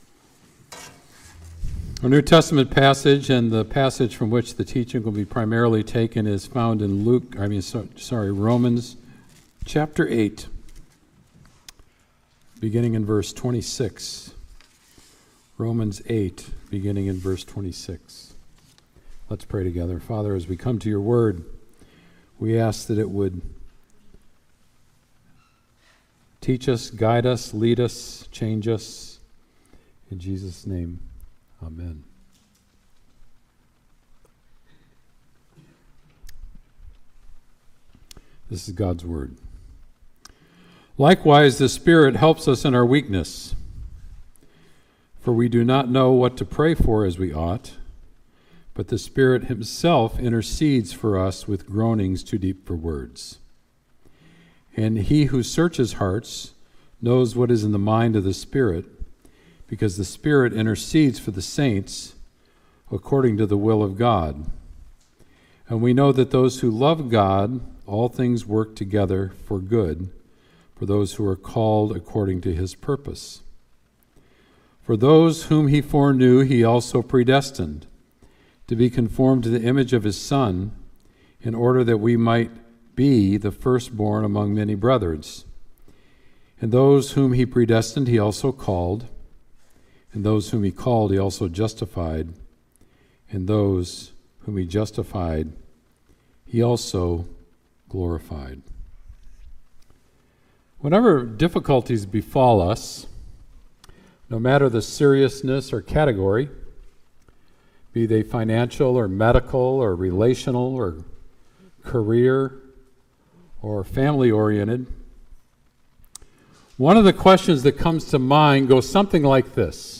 Sermon “Love and Compassion”